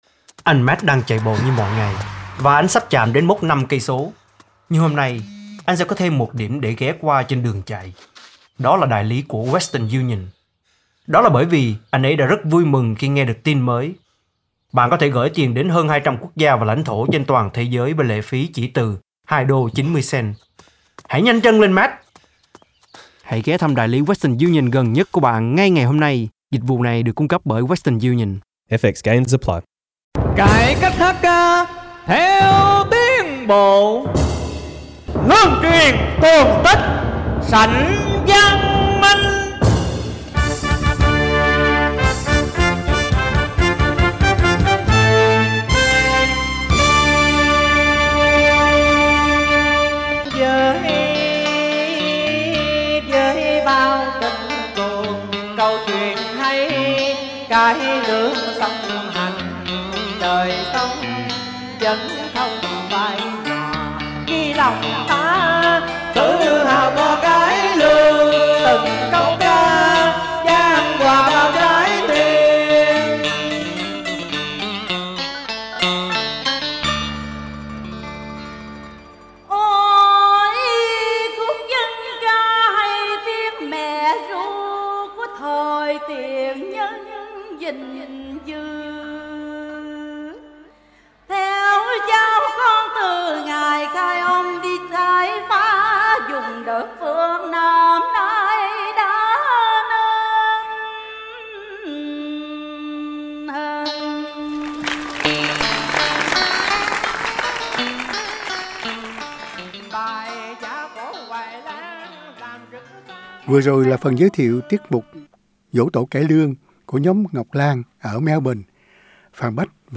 Cổ Nhạc Việt Nam tại Úc